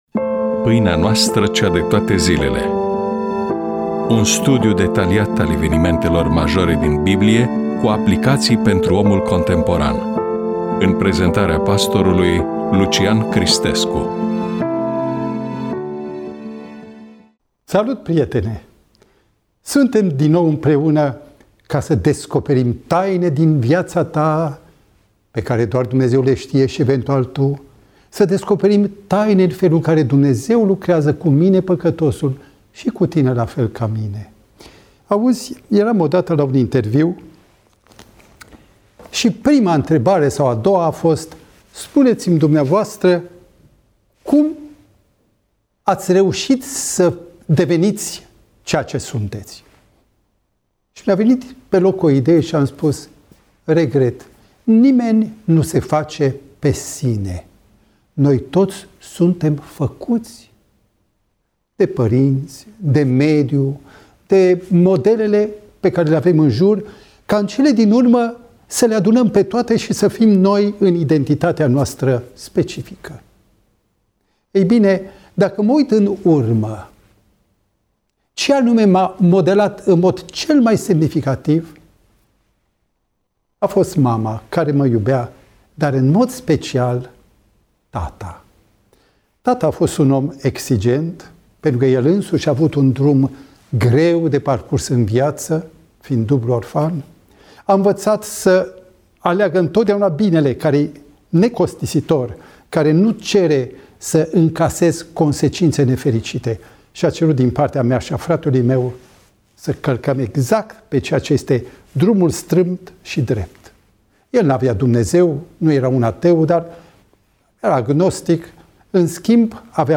EMISIUNEA: Predică DATA INREGISTRARII: 03.04.2026 VIZUALIZARI: 13